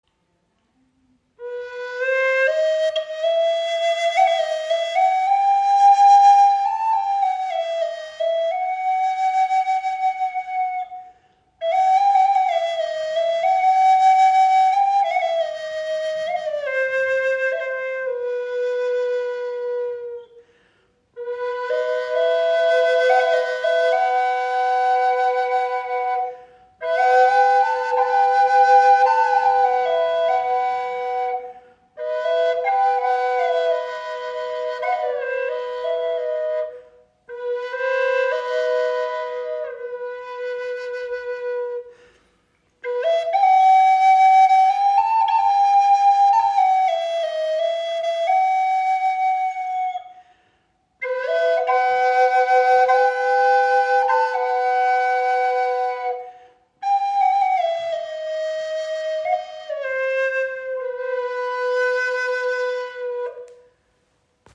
Doppelflöte in C - Hijaz - 432 Hz im Raven-Spirit WebShop • Raven Spirit
Klangbeispiel
Diese wundervolle Doppelflöte ist auf C Hijaz in 432 Hz gestimmt und erzeugt einen warmen, tragenden Klang.